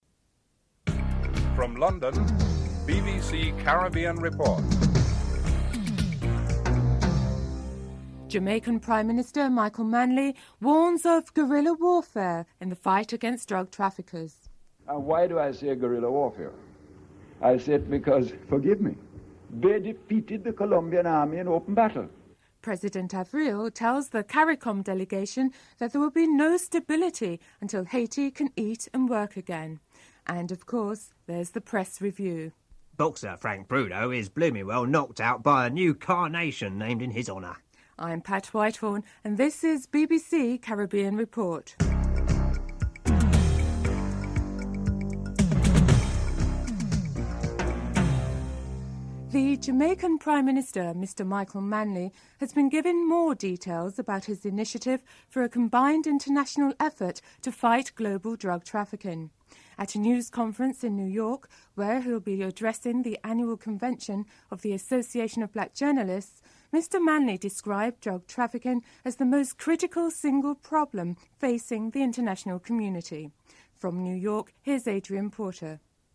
1. Headlines (00:41-01:18)
4. Financial news (06:54-08:23)
6. Press review (11:33-15:03)